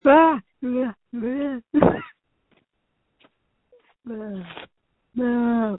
• When you call, we record you making sounds. Hopefully screaming.
• This website is an archive of the recordings we received from hundreds of thousands of callers.